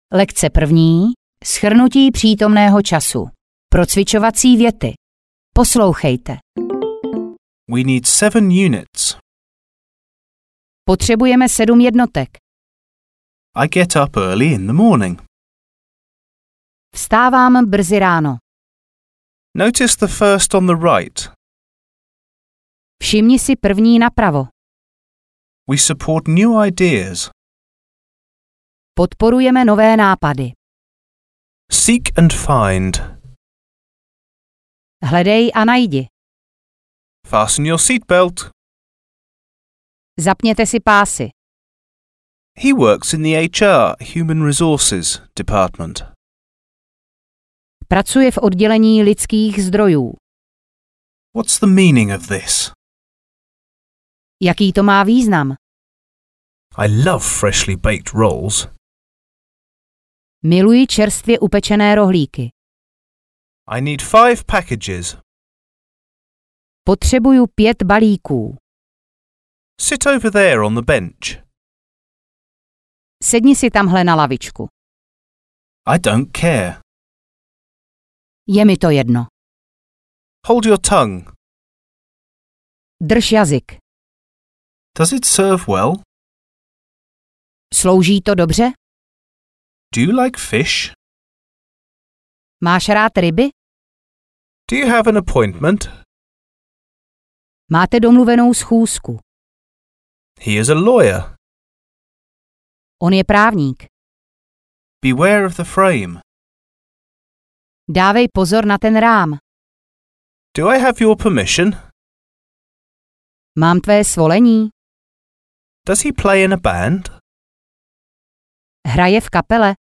Anglická gramatika B1 audiokniha
Ukázka z knihy